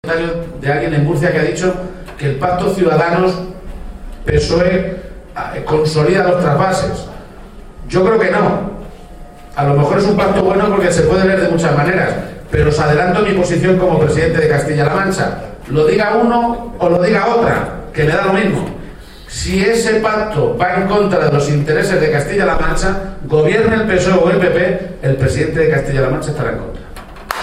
Durante un encuentro con militantes y simpatizantes del PSOE, celebrado en el Mesón Casa Antonio de Tobarra (Albacete)
Cortes de audio de la rueda de prensa